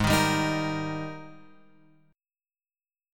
G# Major 7th Suspended 2nd